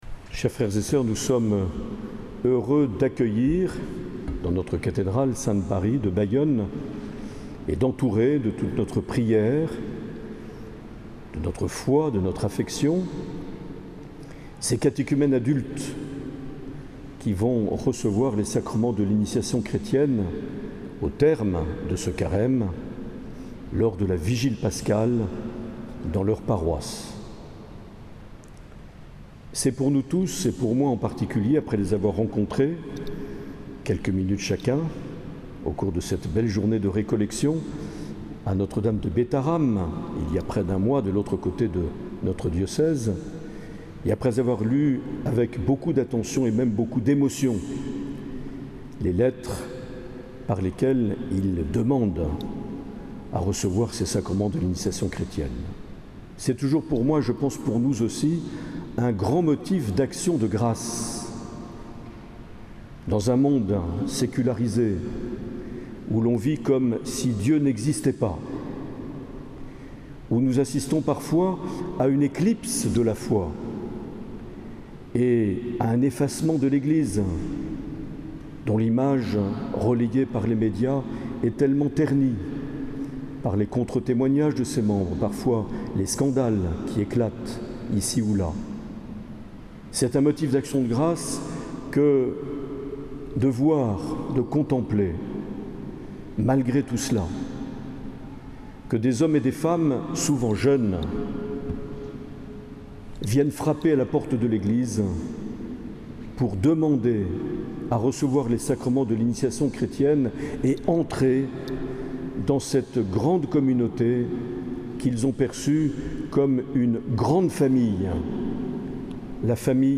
21 février 2021 - Cathédrale de Bayonne - Appel décisif des catéchumènes
Les Homélies
Une émission présentée par Monseigneur Marc Aillet